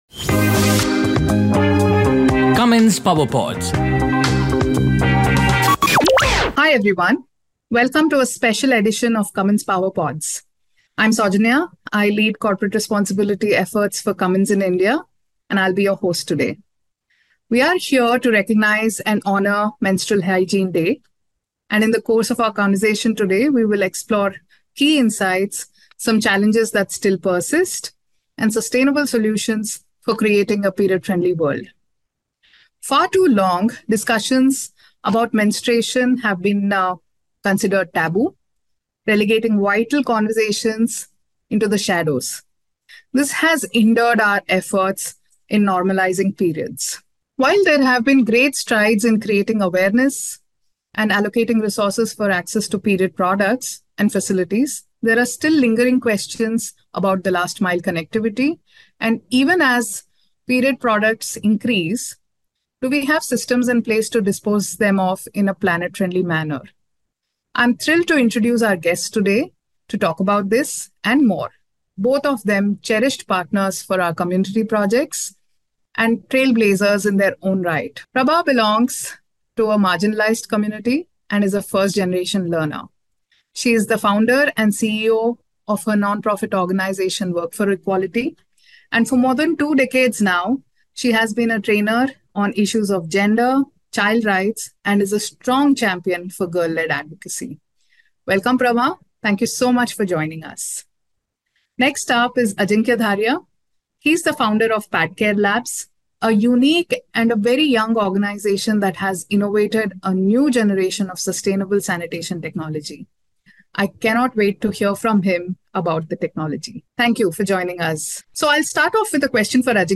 Dive into a thought-provoking conversation as they emphasize on normalizing menstruation, exploring the role of men in shifting the narrative, enhancing access to hygiene products and increasing awareness.